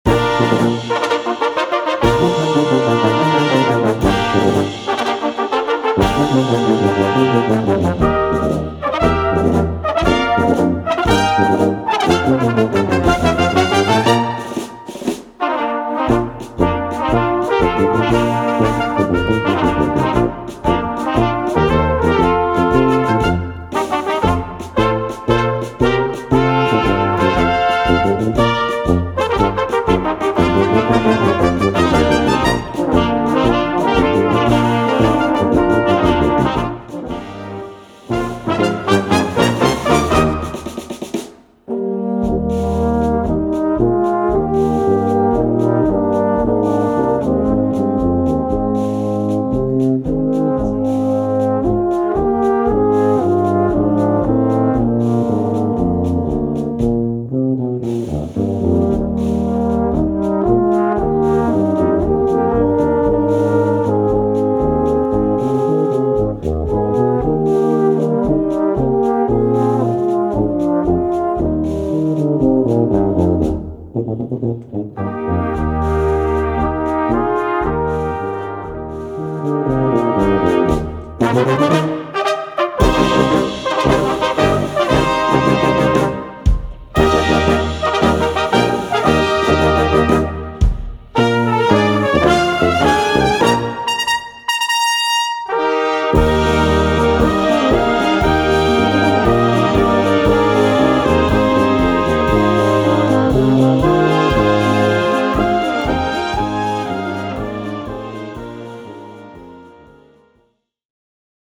Für kleine Besetzung